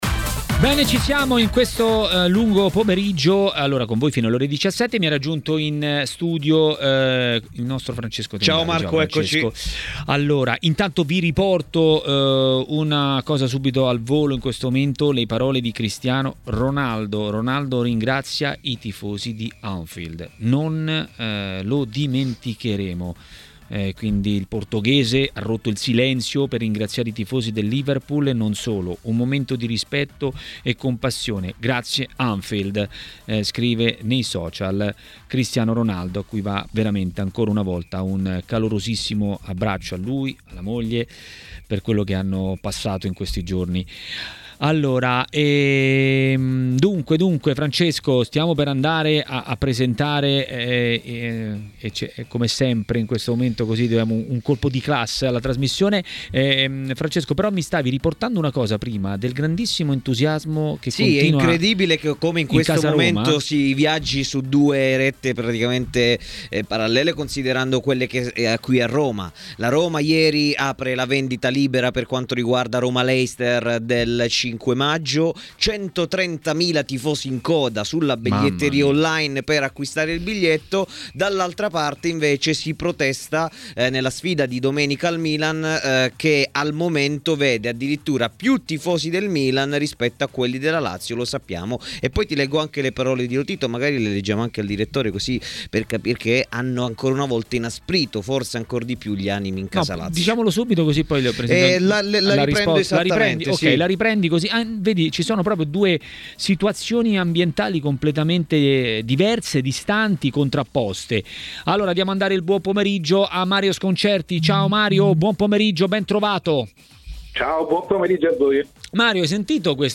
Per commentare le notizie del giorno a Maracanà, trasmissione di TMW Radio, è intervenuto il direttore Mario Sconcerti.